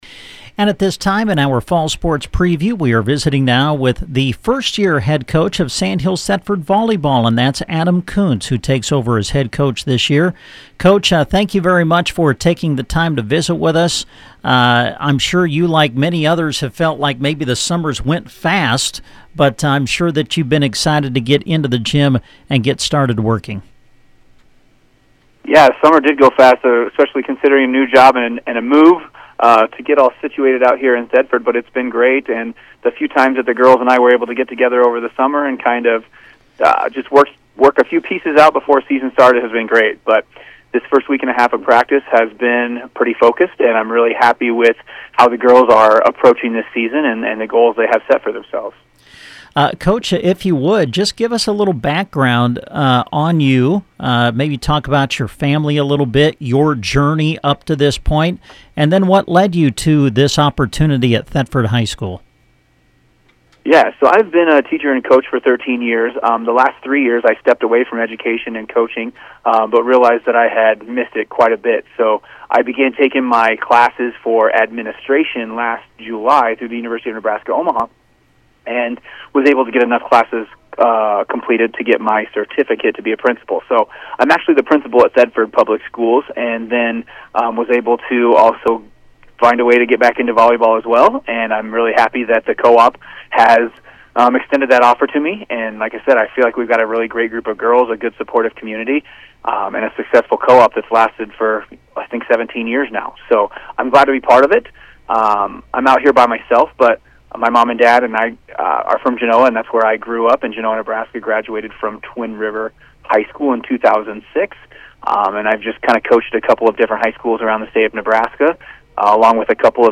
Sandhills/Thedford Volleyball Preview